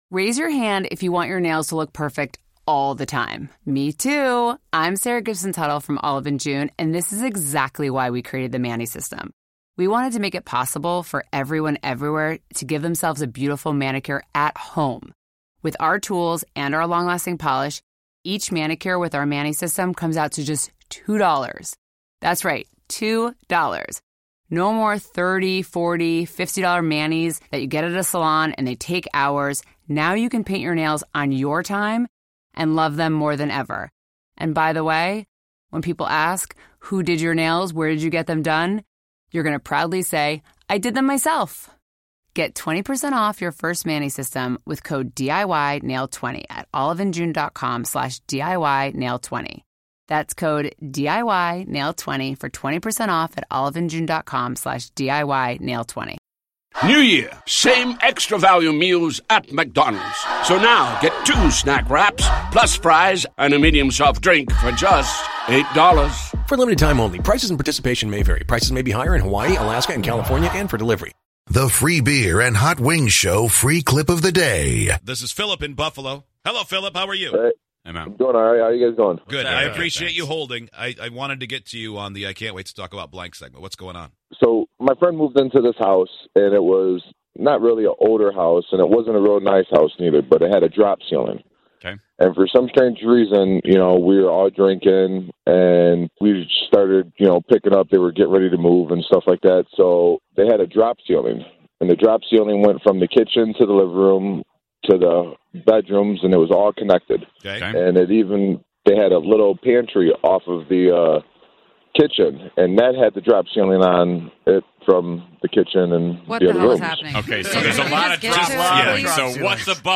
On today's show, a listener called in to tell us about the time he found coke in a drop ceiling.